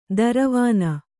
♪ daravāna